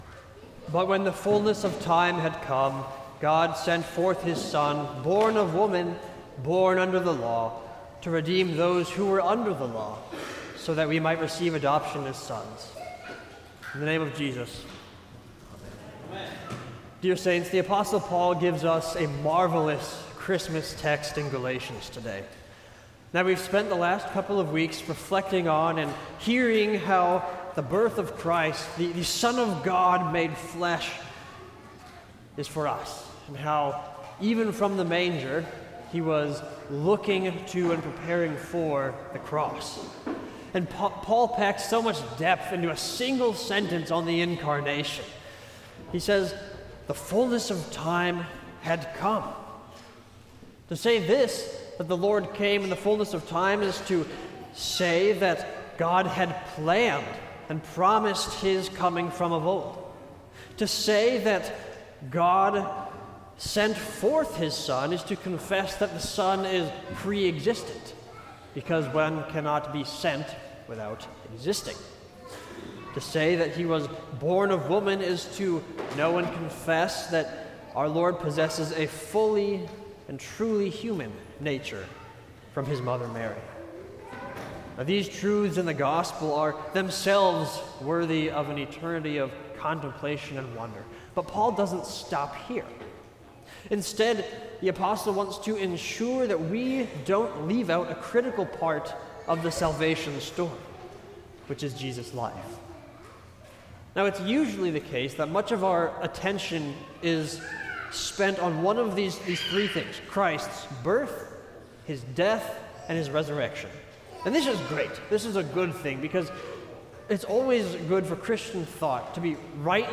Sermon for Second Sunday of Christmas